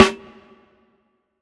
TC SNARE 26.wav